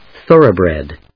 音節thórough・brèd 発音記号・読み方
/θˈɚːrə‐(米国英語), ˈθɜ:əʊˈbred(英国英語)/